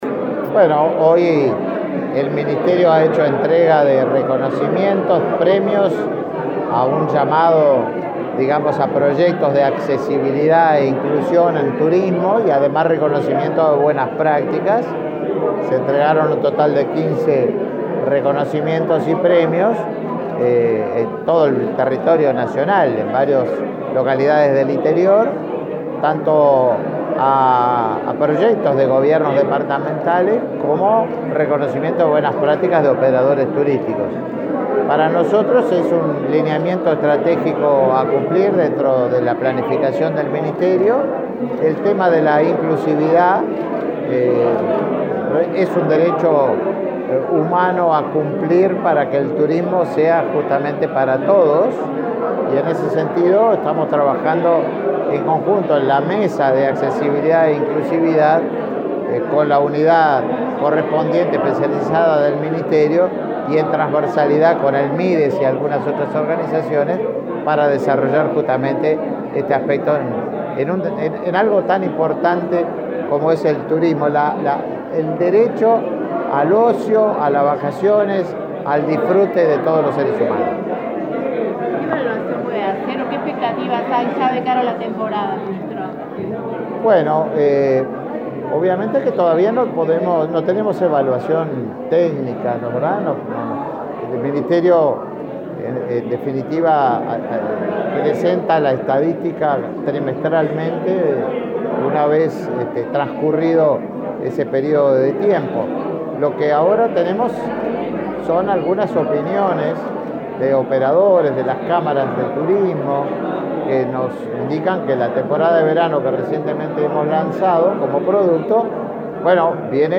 Declaraciones del ministro de Turismo
El ministro Tabaré Viera señaló a la prensa la importancia de esta iniciativa.